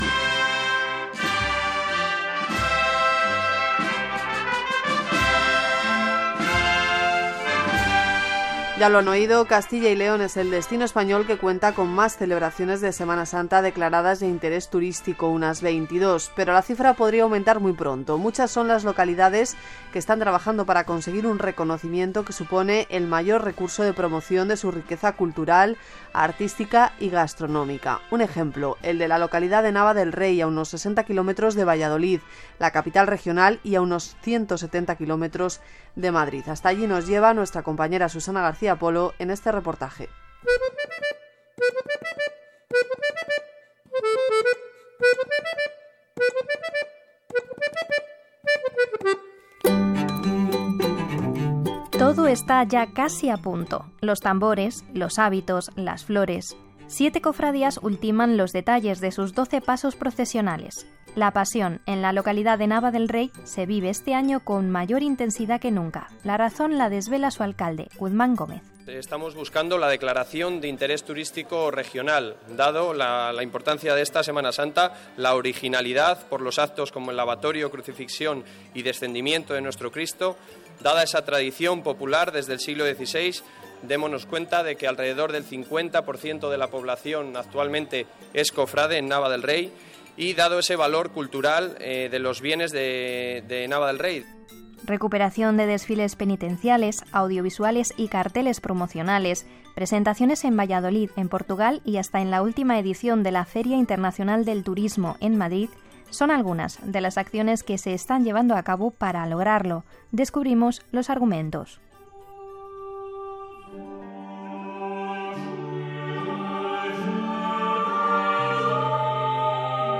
Fragmento del reportaje sobre Semana Santa emitido por RNE